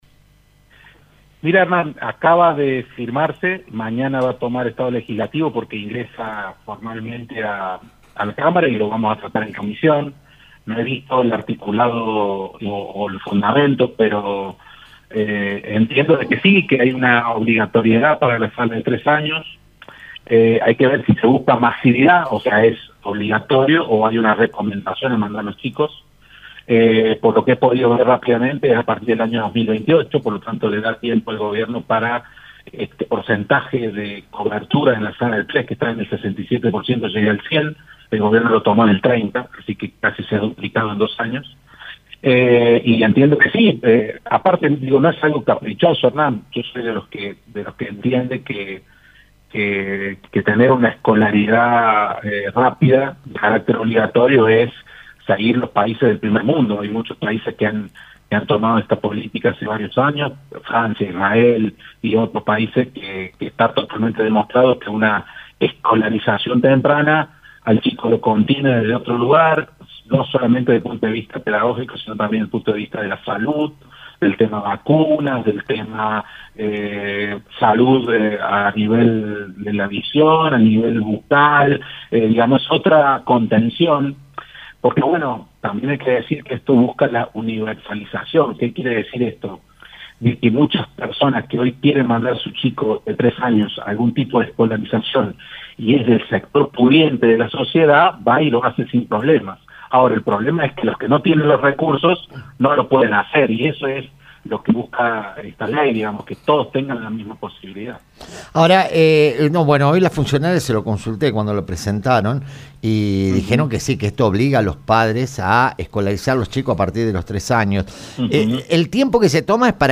El diputado provincial Víctor Moriñigo confirmó en “El Show de la Tarde” que la iniciativa tomará estado legislativo este miércoles. El proyecto apunta a la universalización de la educación inicial para garantizar igualdad de oportunidades y contención sanitaria desde los primeros años.
En una entrevista picante